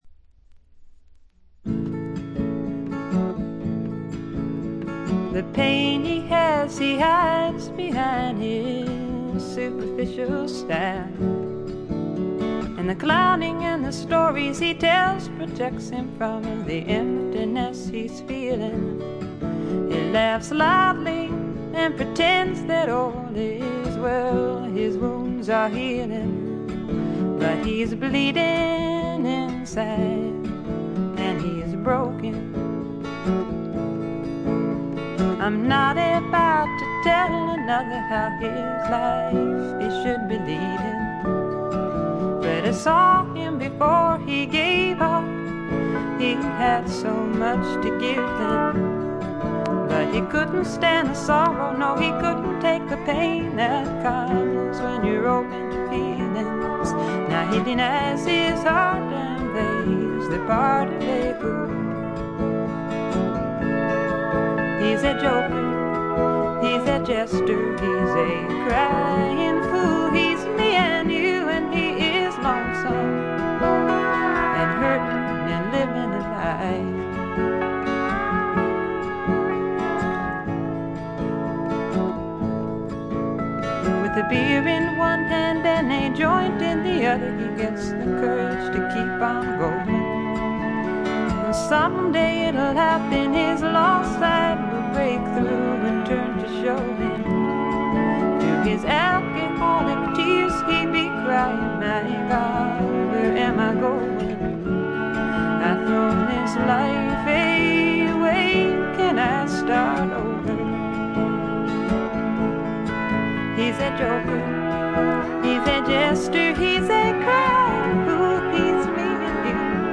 女性シンガー・ソングライター、フィメール・フォーク好きには必聴／必携かと思います。
試聴曲は現品からの取り込み音源です。
Recorded at Bearsville Sound Studios, Woodstock, N.Y.
Vocals, Acoustic Guitar